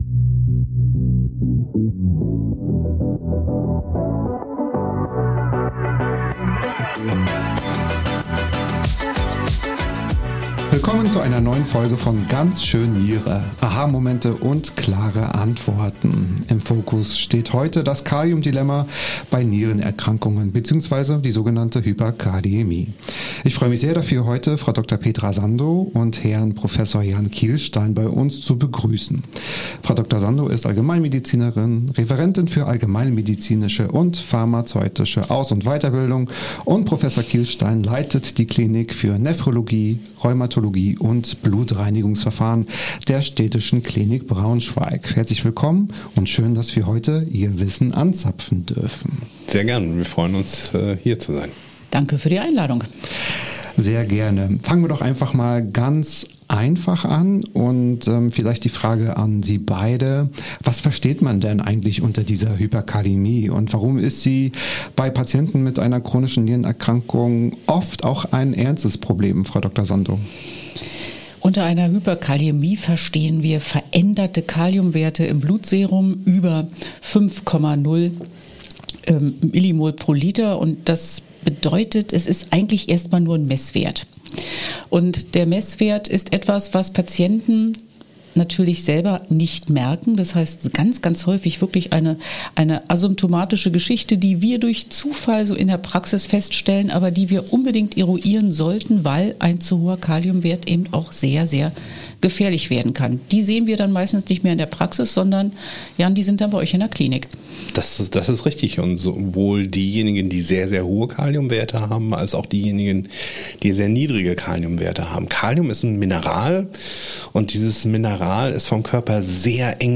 Die beiden Expert:innen sprechen über Ursachen wie Ernährung, Medikamente und Messfehler, geben praxisnahe Einblicke aus Klinik und Hausarztpraxis und räumen mit Mythen rund um Kalium auf. Im Mittelpunkt steht dabei immer die Frage, wie man betroffene Patient:innen sicher versorgt, ohne lebenswichtige Medikamente vorschnell abzusetzen. Ein informativer Austausch mit konkreten Beispielen und klaren Empfehlungen für Patient*innen, Fachpersonal und interessierte Zuhörer:innen.